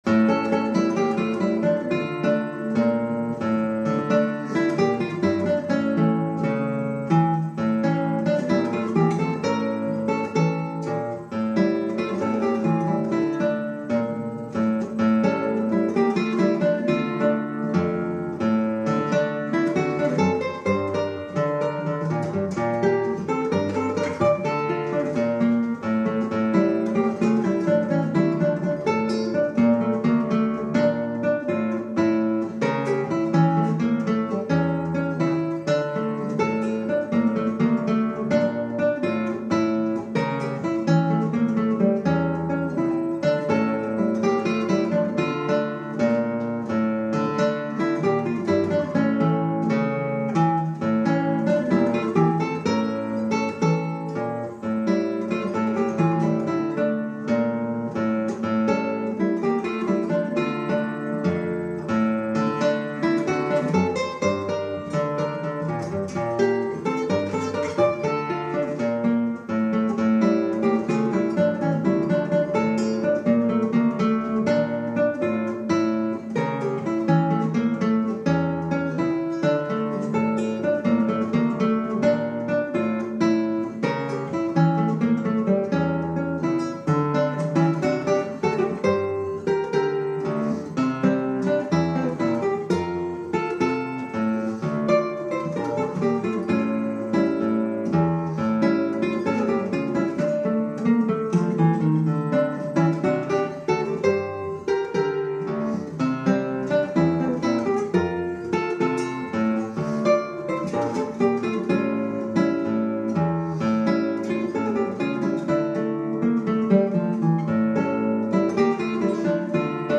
Drei Stücke für Gitarre solo